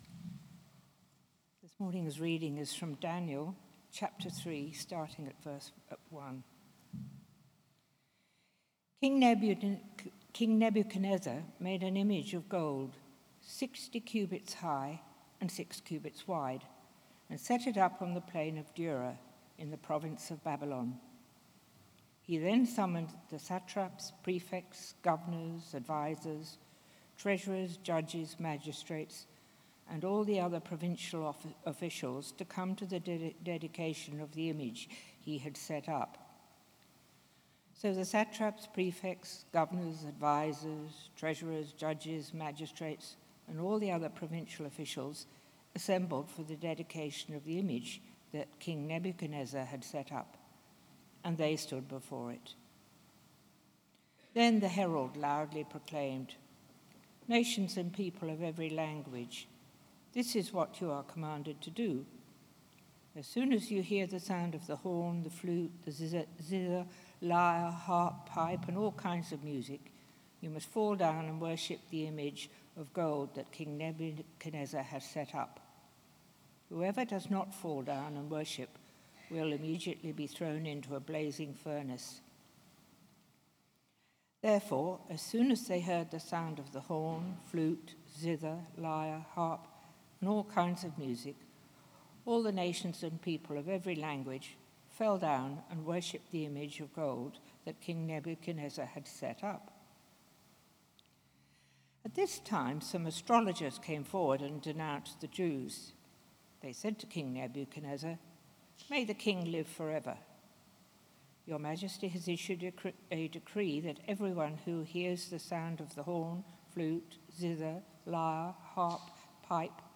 A sermon on Daniel 3:1-30